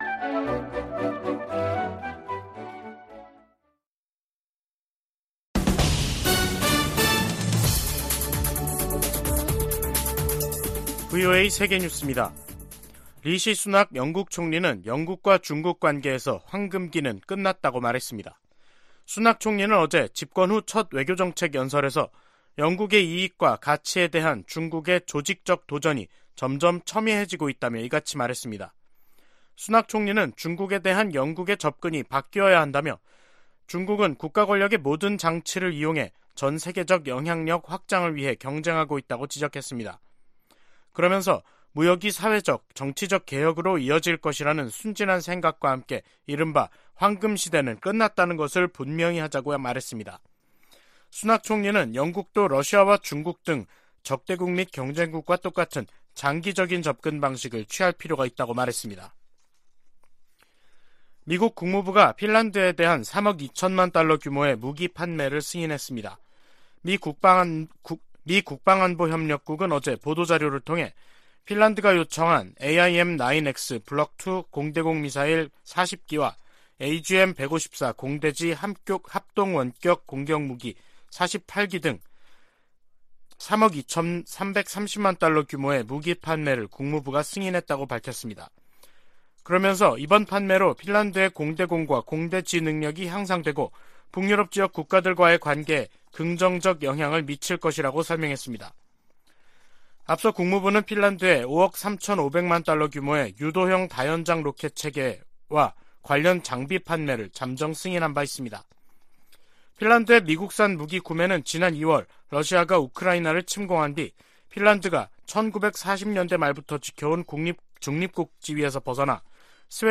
VOA 한국어 간판 뉴스 프로그램 '뉴스 투데이', 2022년 11월 29일 2부 방송입니다. 김정은 북한 국무위원장은 최근의 대륙간탄도미사일 시험발사를 현지 지도하며 대륙간 탄도미사일 부대를 처음 언급했습니다. 북한의 장거리 탄도미사일 발사가 미국 본토에 대한 위협이 되지 않으나 북한이 역내에 제기하는 위협을 우려한다고 백악관 고위 관리가 밝혔습니다.